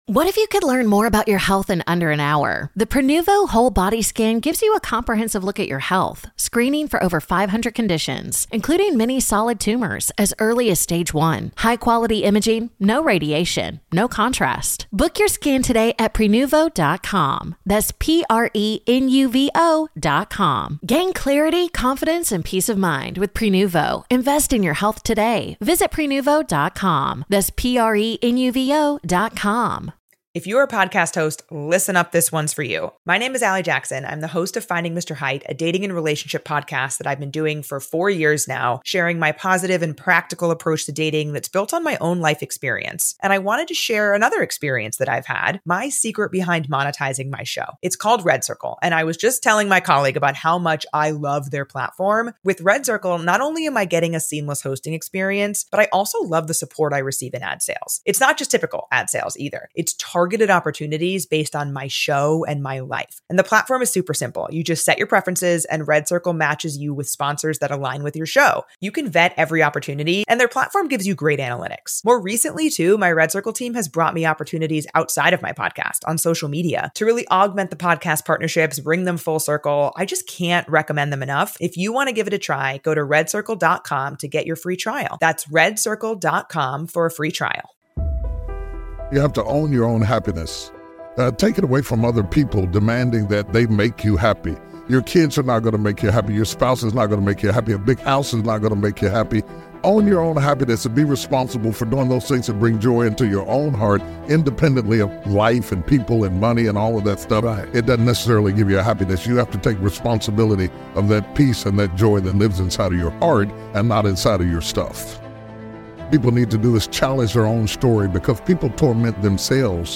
Bishop T.D. Jakes - Challenge your own story motivational speech